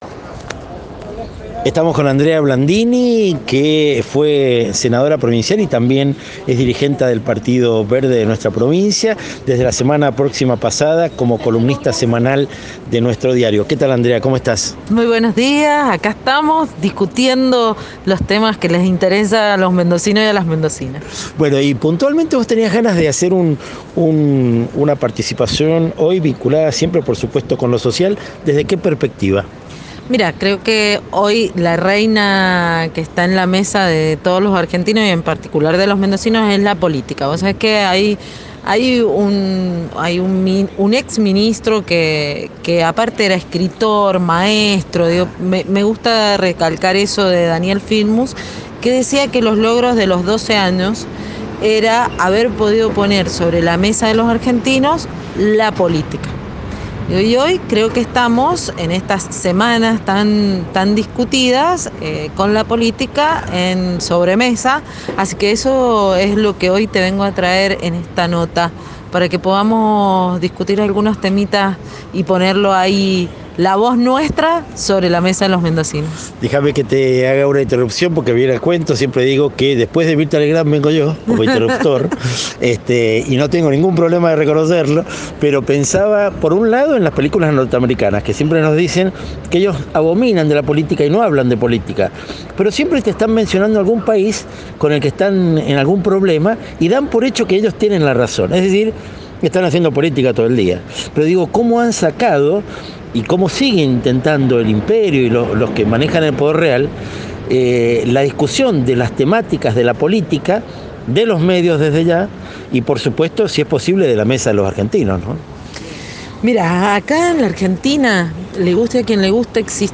Entrevista: Andrea Blandini, dirigenta del Partido Verde y ex senadora provincial, 25 de agosto de 2022